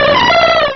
pokeemerald / sound / direct_sound_samples / cries / jumpluff.aif